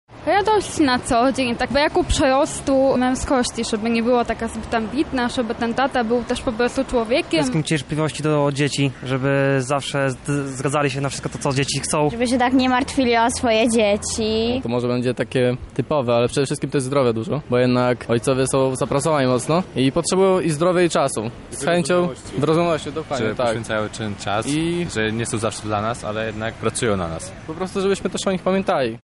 Zapytaliśmy mieszkańców Lublina o to, jakie życzenia złożyliby wszystkim tatom.